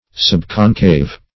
\Sub*con"cave\